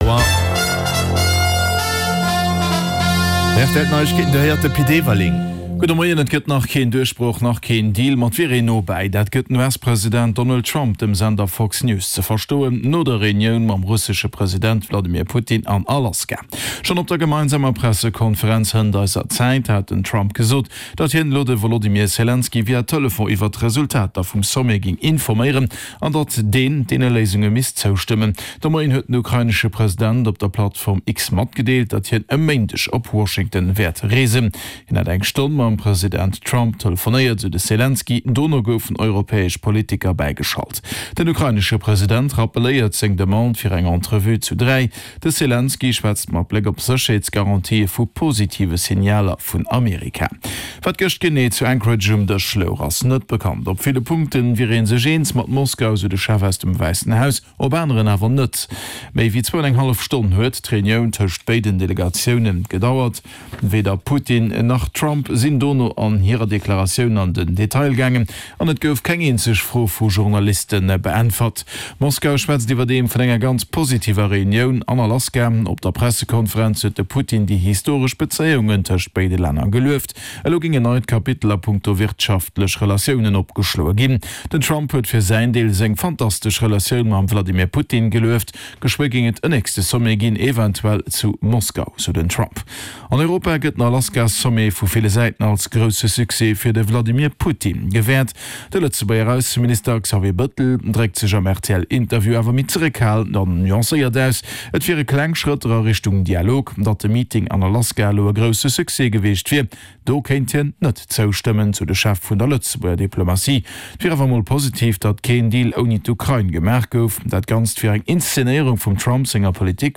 Play Rate Listened List Bookmark Get this podcast via API From The Podcast Den News Bulletin mat allen Headlines aus Politik, Gesellschaft, Economie, Kultur a Sport, national an international Join Podchaser to...